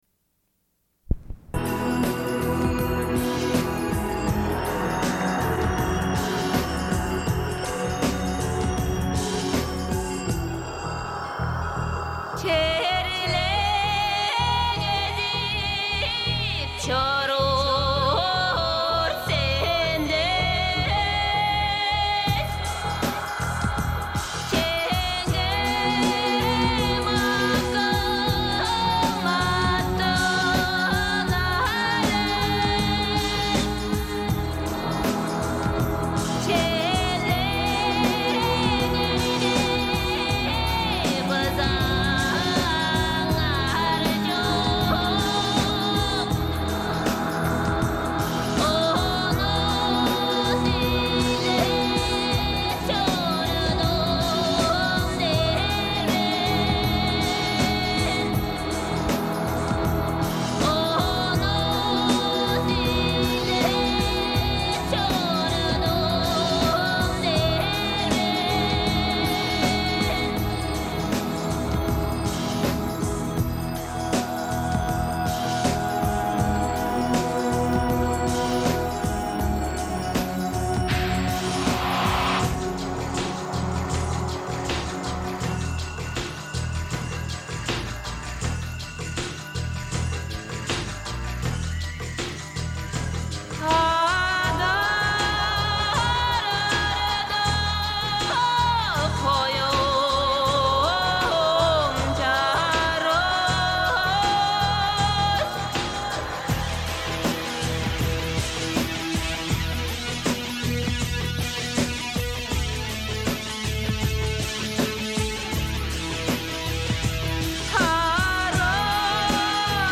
Suite de l'émission : au sujet du droit de vote des femmes en Suisse, obtenu le 7 février 1971, à l'occasion du 25ème. Revue de presse (Femmes suisses, Le Courrier, Tribune de Genève, Journal de Genève...).
Une cassette audio, face A
Radio